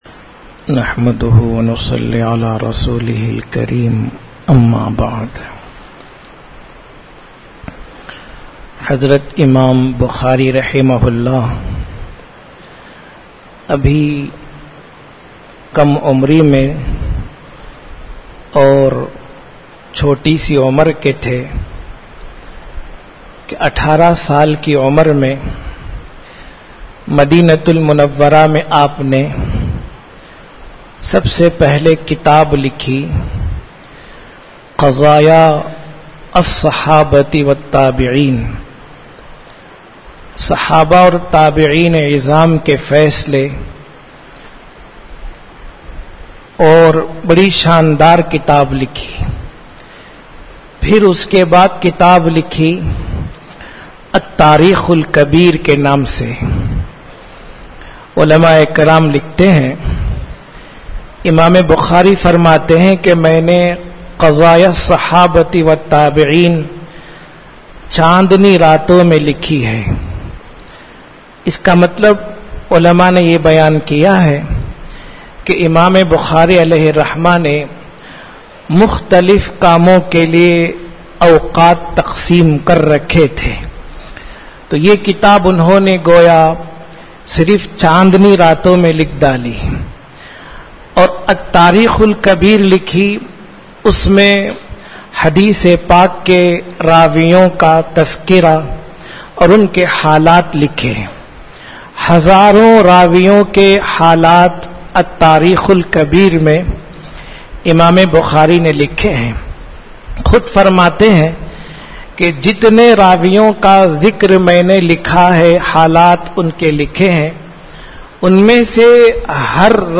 Urdu Bayans Majlis-e-Jamiulkhair, Jamiya Mosque, Ambur.